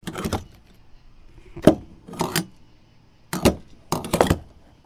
AttachPump.wav